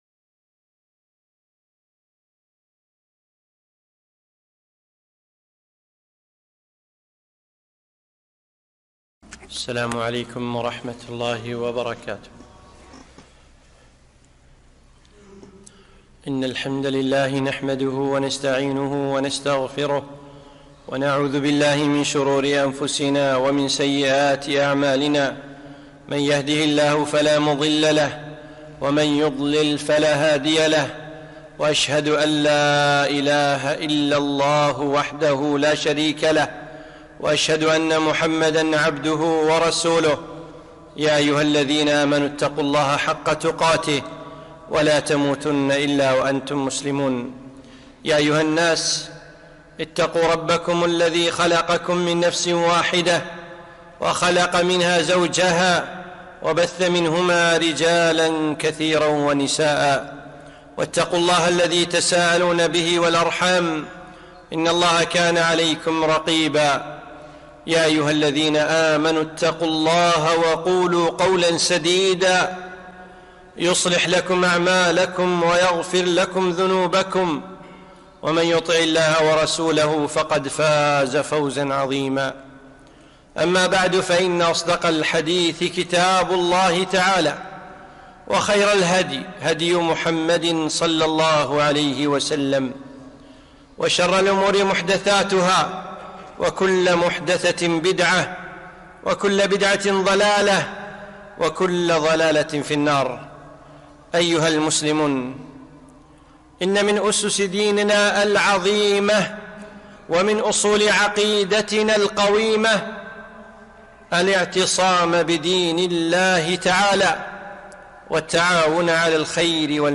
خطبة - يا أهل الكويت